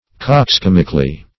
\Cox*com"ic*al*ly\